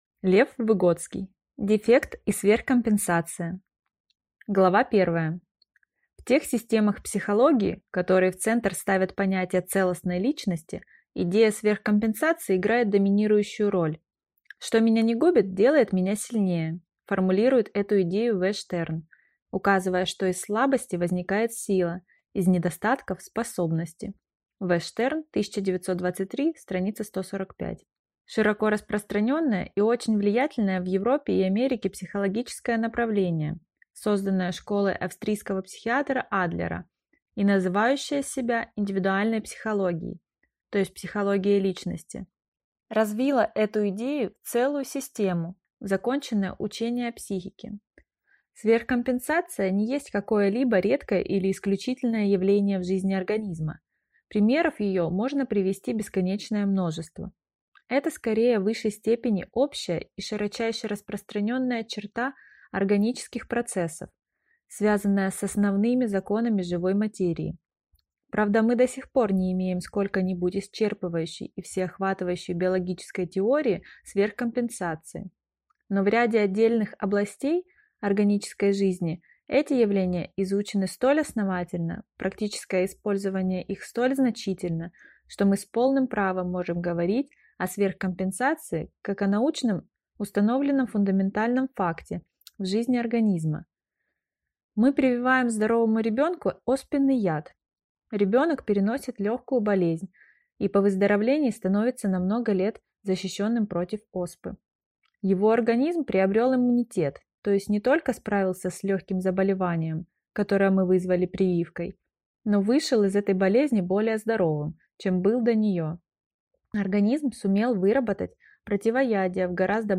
Аудиокнига Дефект и сверхкомпенсация | Библиотека аудиокниг